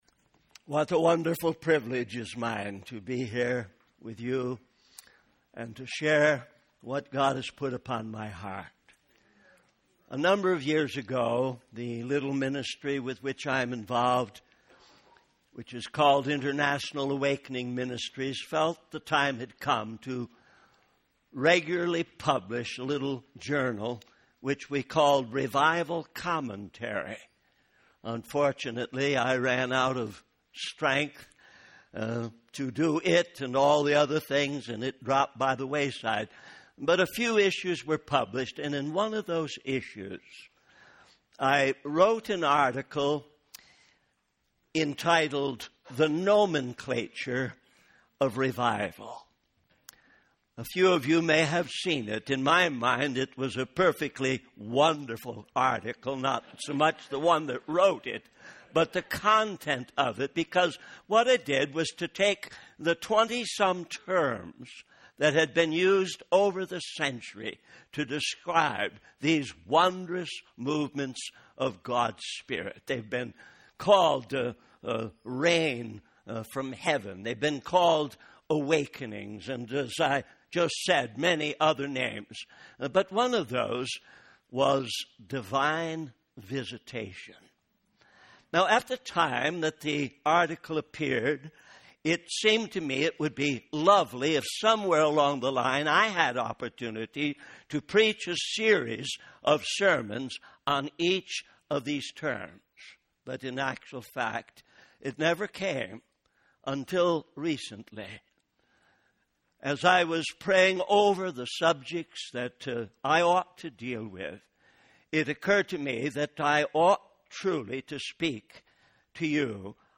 In this sermon, the preacher discusses the topic of divine visitations.